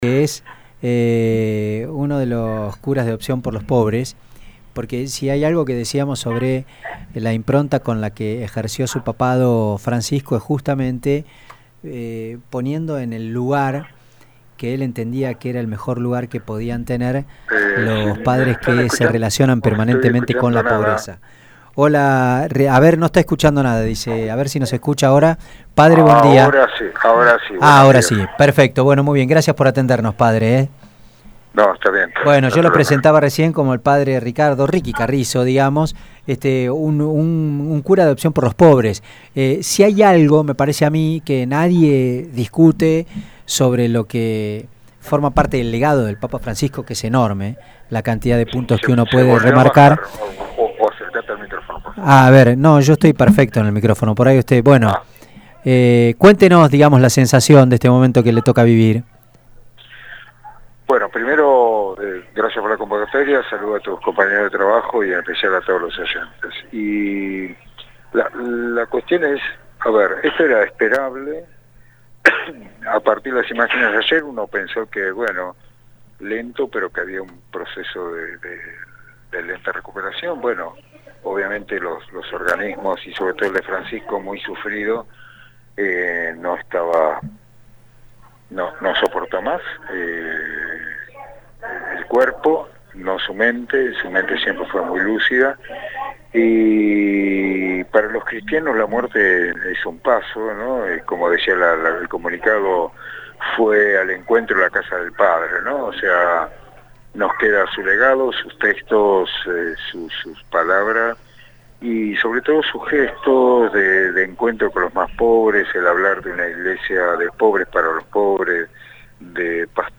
en dialogo con el cura